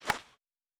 Puck Hit Normal.wav